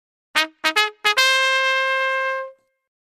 Trumpet Sound